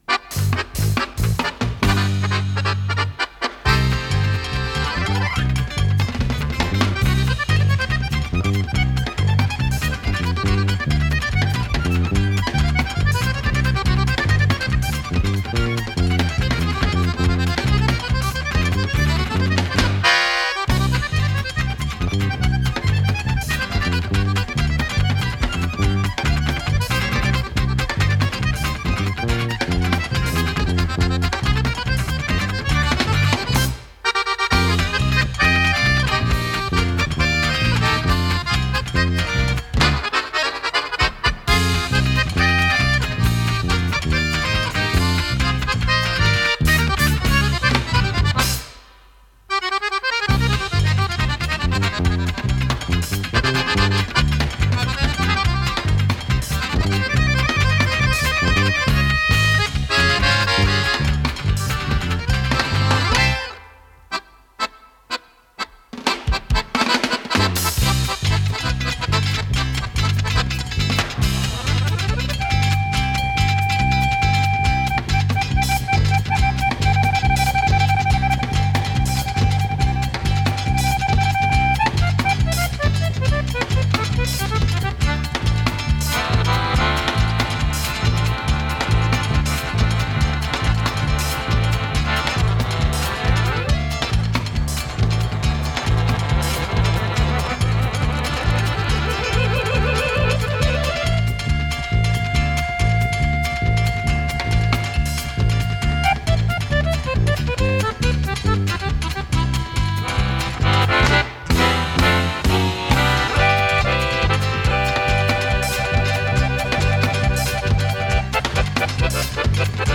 с профессиональной магнитной ленты
аккордеон
шестиструнная гитара
контрабас
ударные
ВариантДубль моно